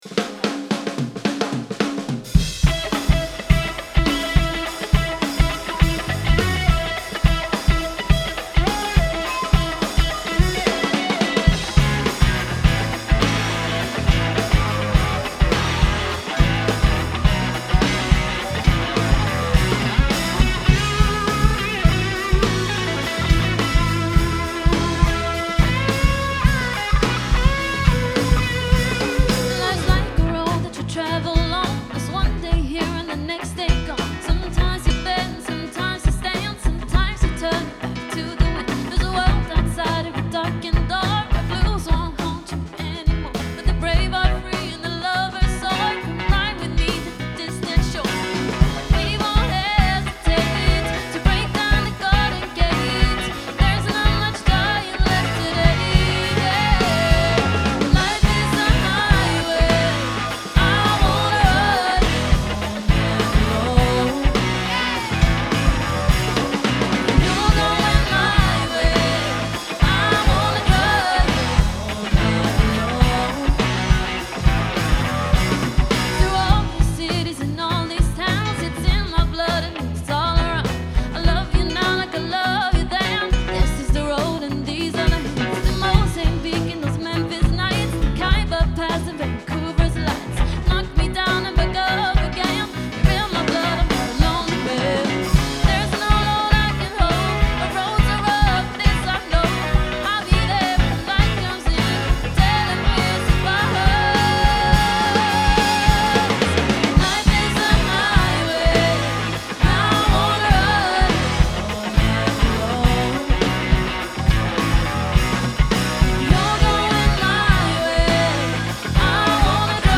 Partyband som svänger
• Coverband